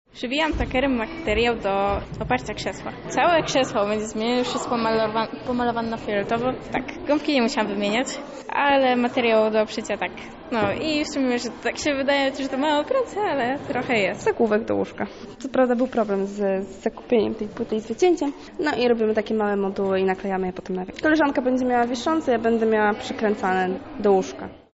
O swoich zadaniach mówią uczestniczki: